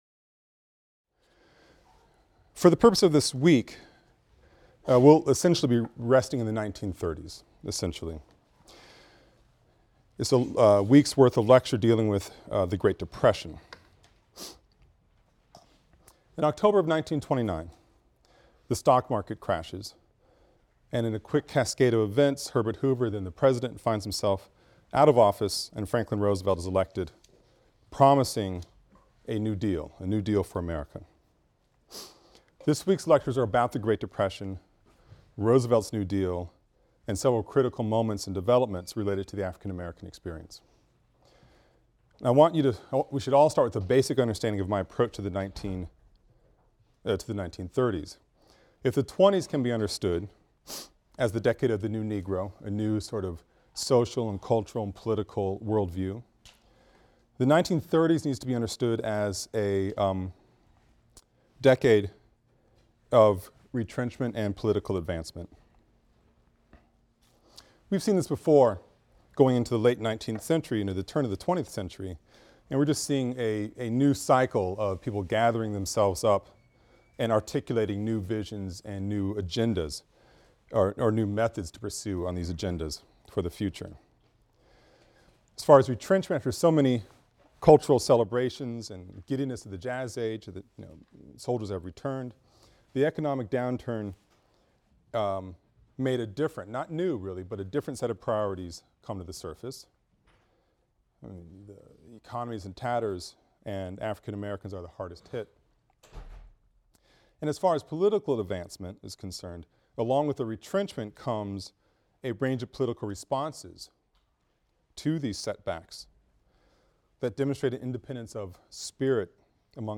AFAM 162 - Lecture 11 - Depression and Double V | Open Yale Courses